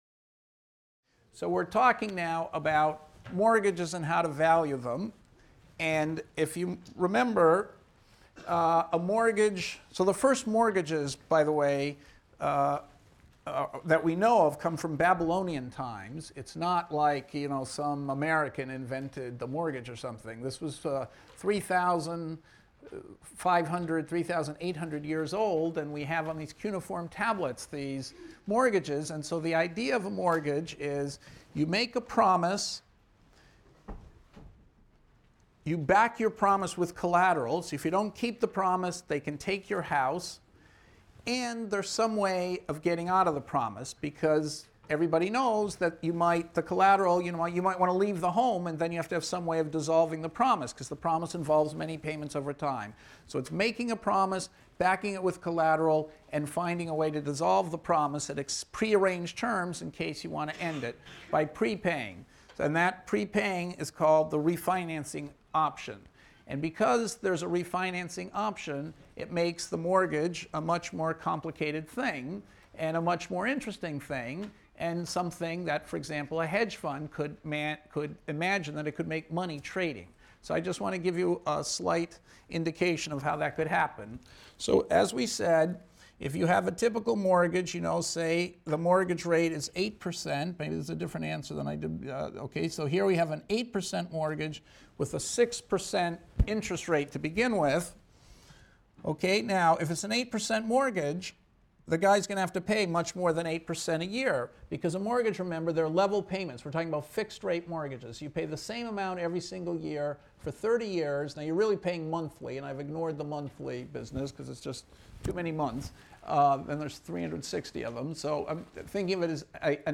ECON 251 - Lecture 18 - Modeling Mortgage Prepayments and Valuing Mortgages | Open Yale Courses